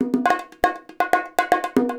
120 BONGOS4.wav